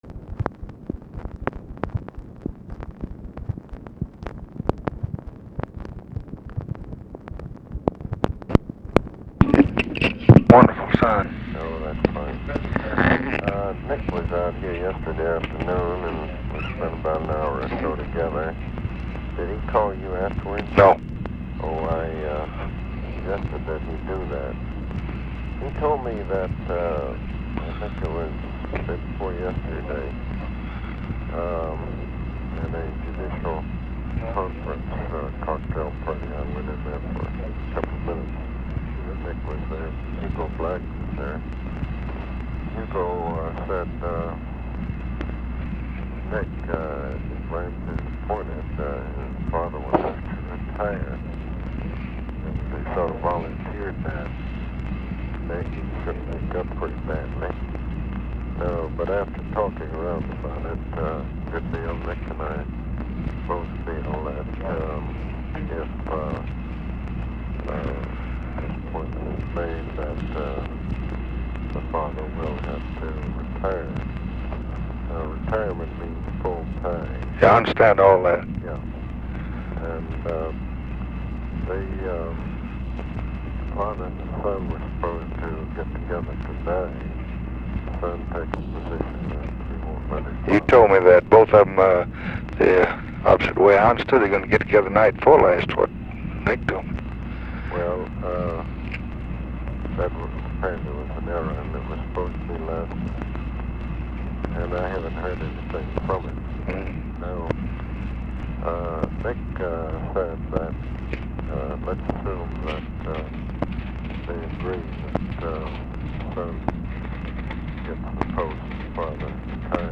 Conversation with ABE FORTAS, September 24, 1966
Secret White House Tapes